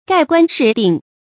蓋棺事定 注音： ㄍㄞˋ ㄍㄨㄢ ㄕㄧˋ ㄉㄧㄥˋ 讀音讀法： 意思解釋： 猶蓋棺論定。指人死后對其一生作出評價。